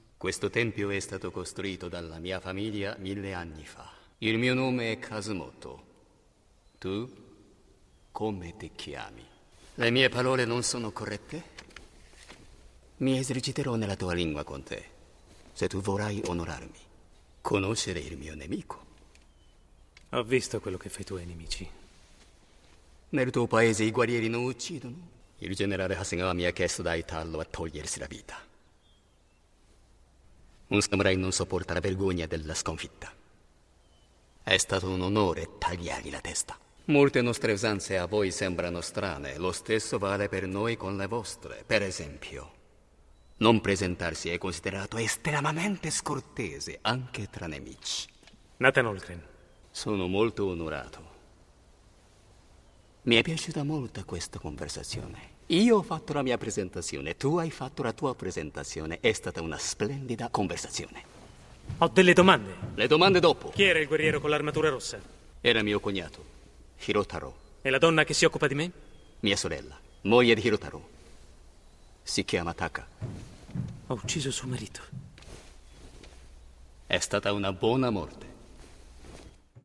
voce di Haruhiko Yamanouchi nel film "L'ultimo samurai", in cui doppia Ken Watanabe.